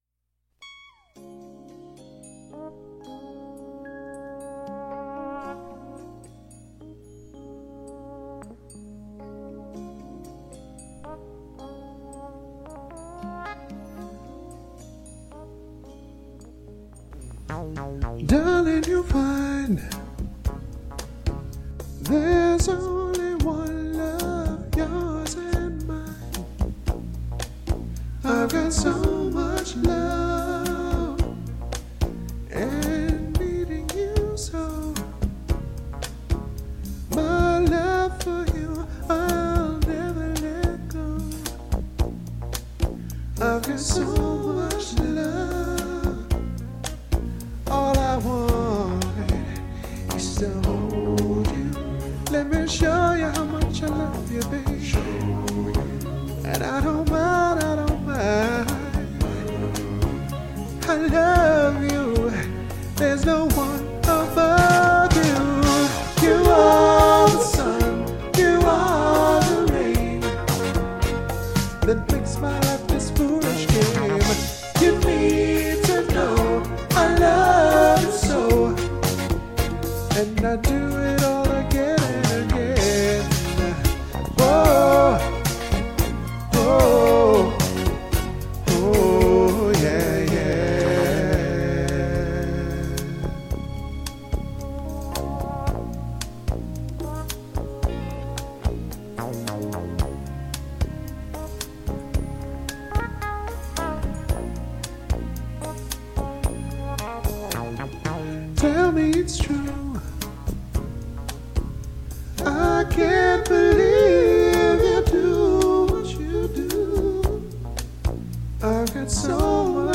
80's
harmonies
karaoke cover
male/solo
pop